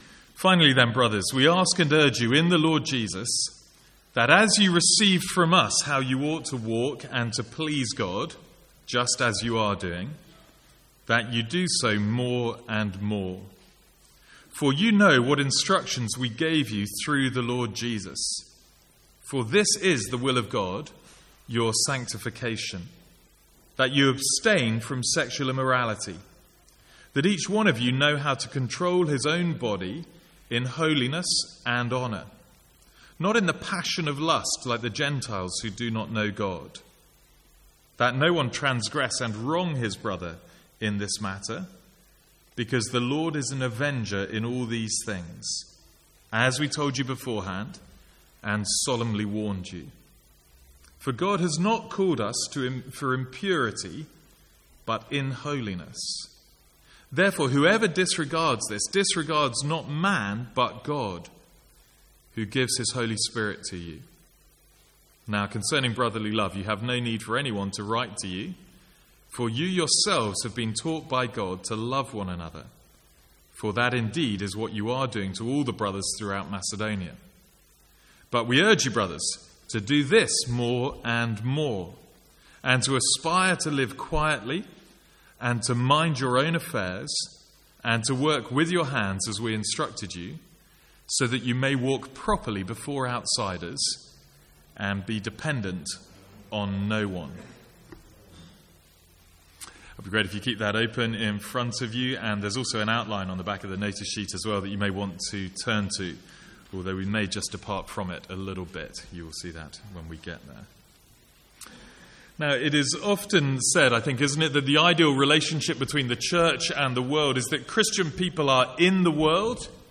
Sermons | St Andrews Free Church
From the Sunday evening series in 1 Thessalonians.